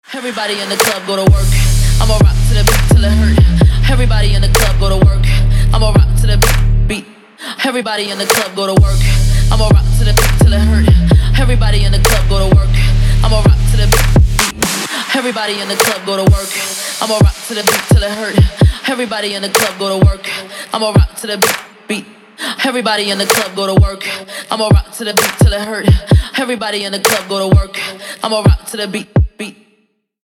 женский вокал
Хип-хоп
Trap
Rap
Bass